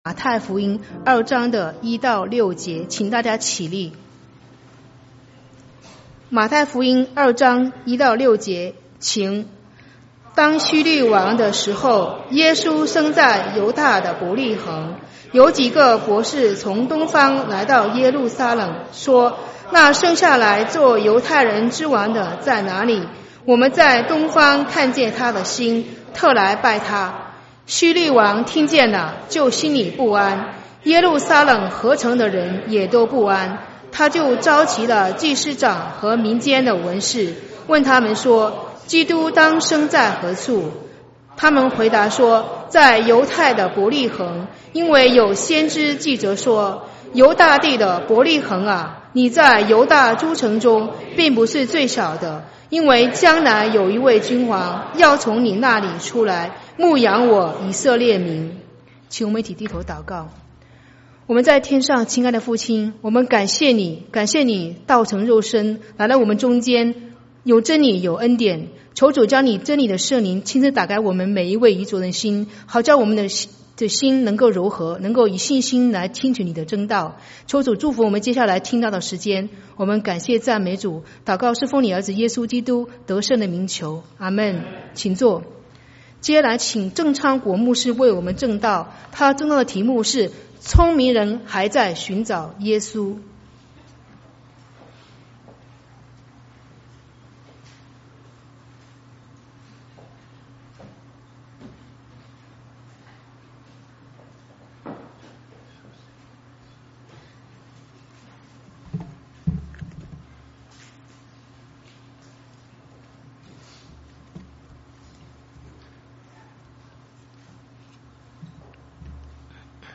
2016 主日證道